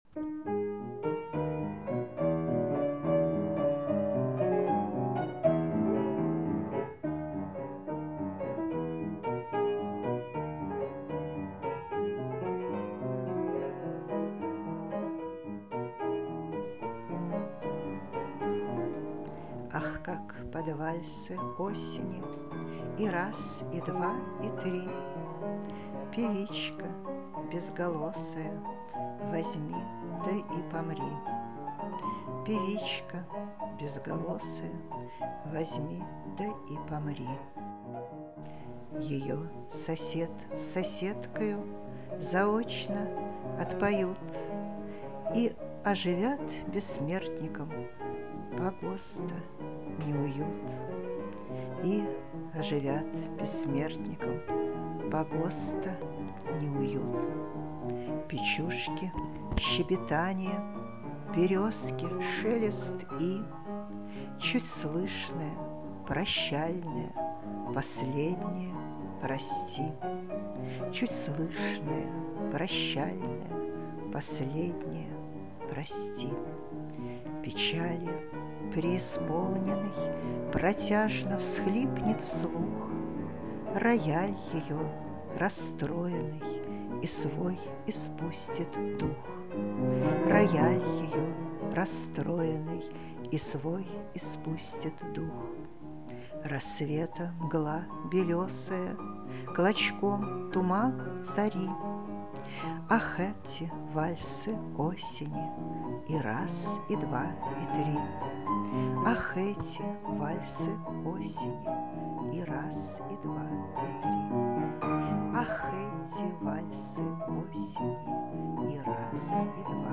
«БАРДОВСКАЯ ПЕСНЯ»